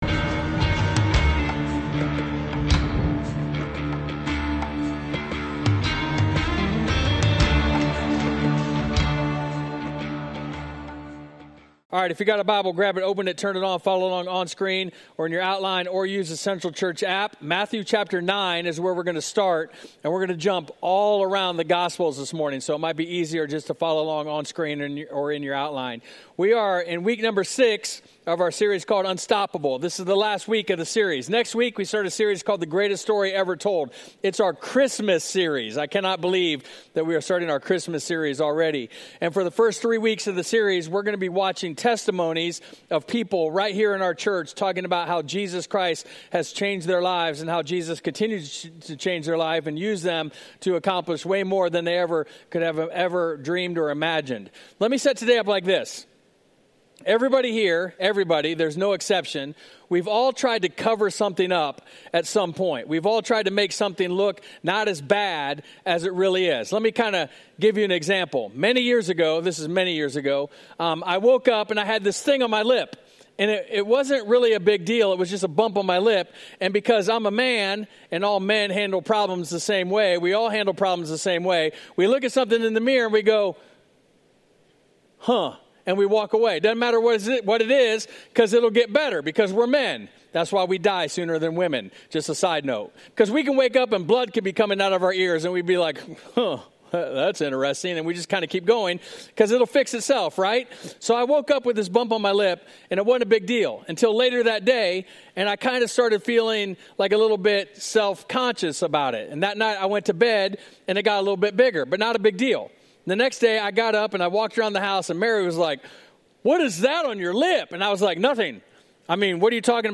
Carroll's Sunday services are 8:00, 9:30 or 11:00 am.